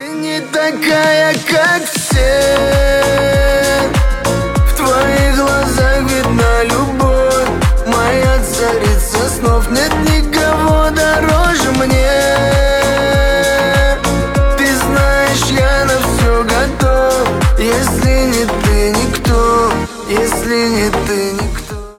на русском восточные на девушку про любовь